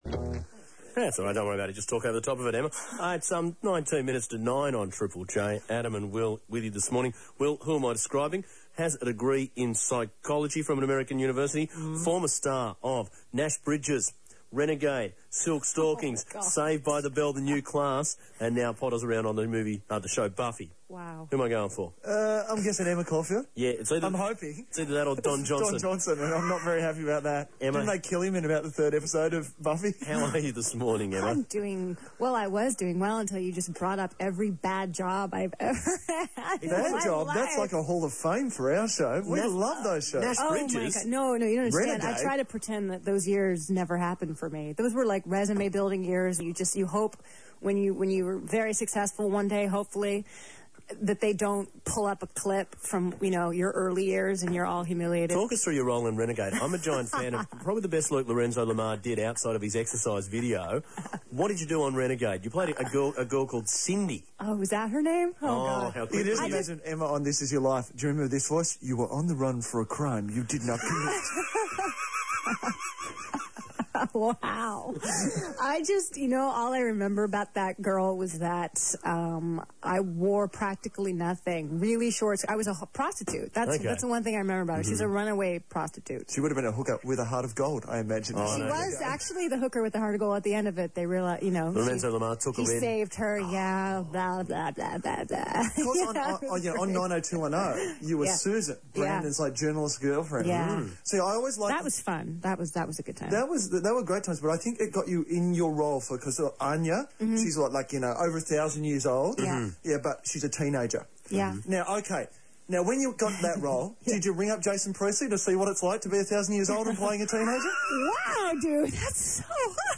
Interview (Radio TripleJ